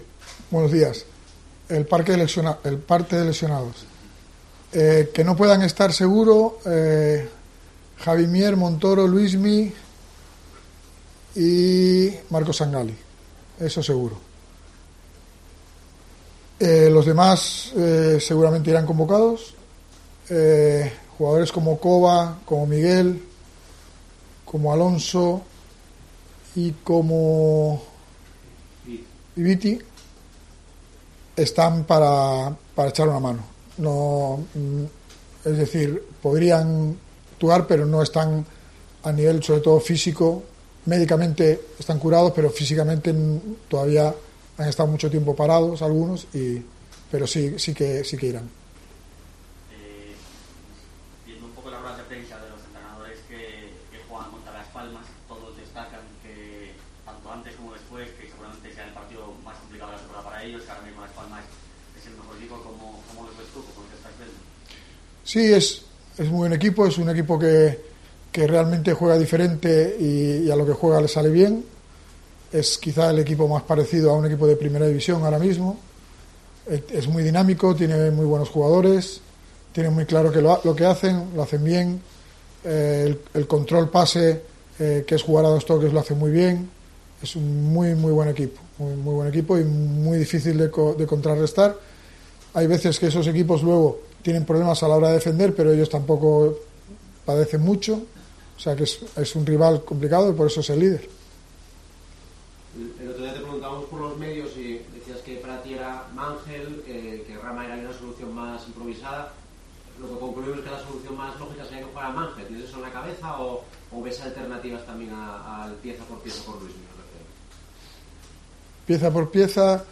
Rueda de prensa Álvaro Cervera (previa Las Palmas)